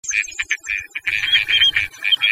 Les canards